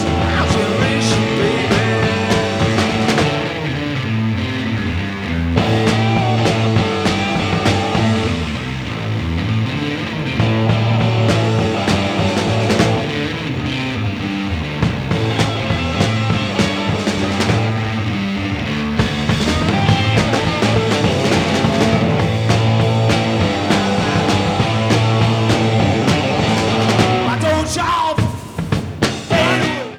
Sound Samples (All Tracks In Stereo)